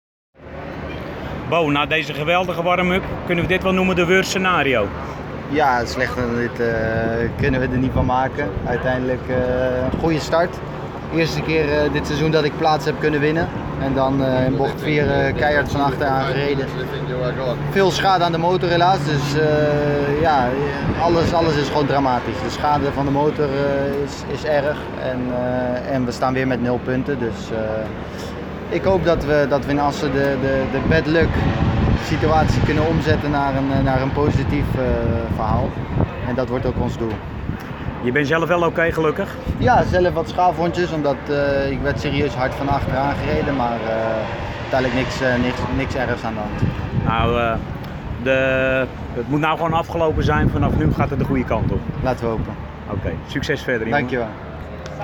Nadat de enige Nederlandse Grand Prix coureur enigszins bekomen was van de schrik spraken we met hem en vroegen hem of hij oké was, en wat er precies gebeurde in de eerste en voor hem enige ronde van de race.